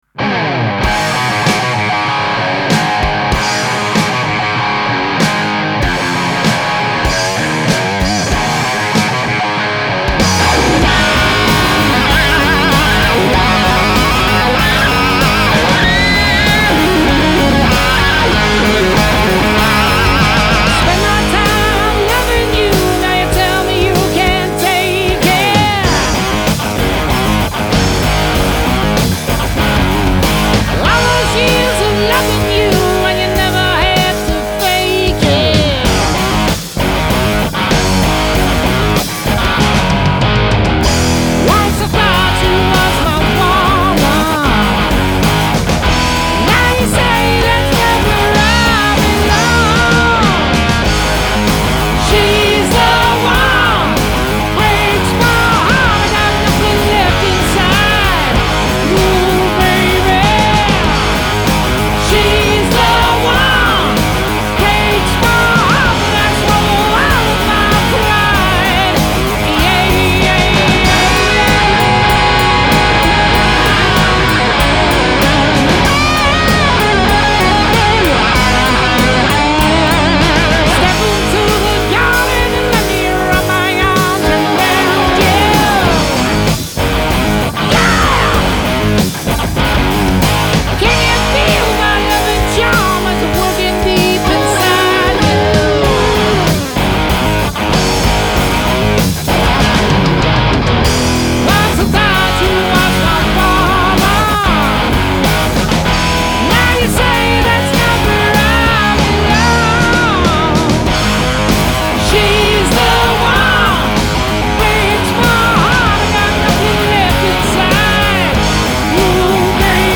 full on, punchy classic hard rock with guitar riffs galore!
lead guitarist
bass
rhythm guitarist